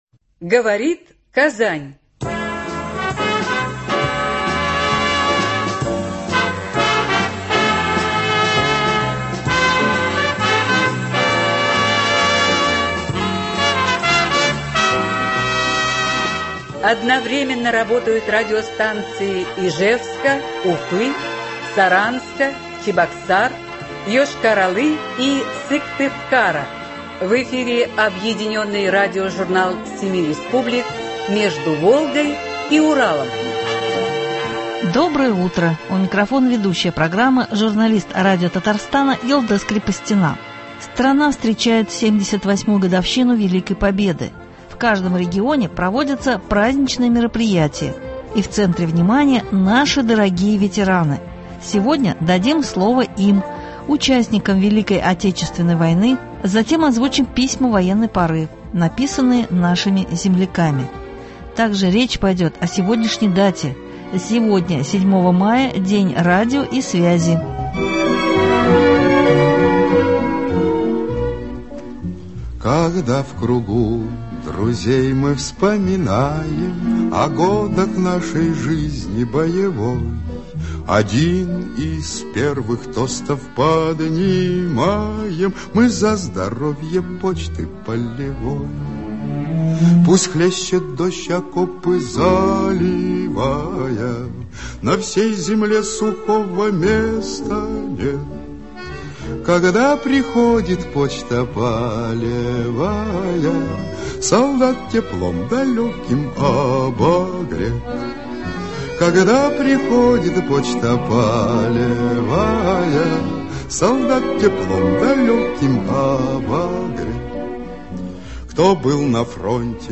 Объединенный радиожурнал семи республик.
Сегодня дадим слово им – участникам Великой Отечественной войны, затем озвучим письма военной поры, написанные нашими земляками.